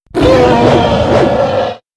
Mutant_Stab.wav